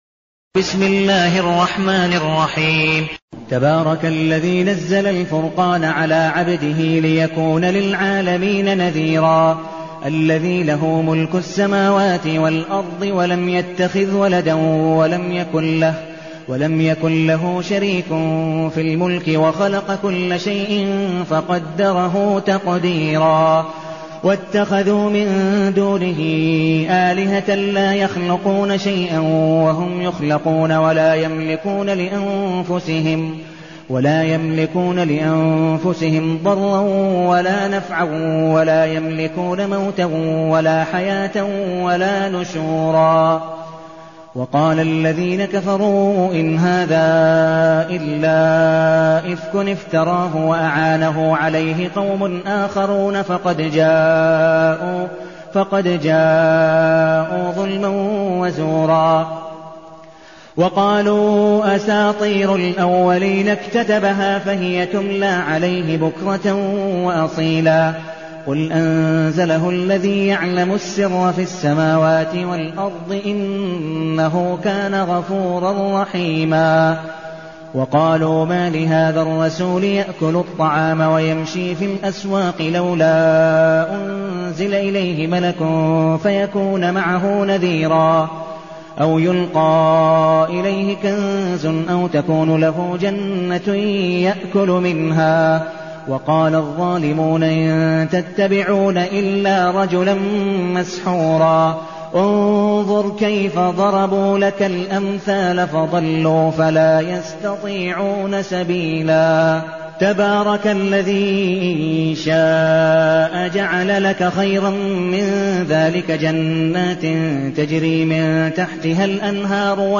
المكان: المسجد النبوي الشيخ: عبدالودود بن مقبول حنيف عبدالودود بن مقبول حنيف الفرقان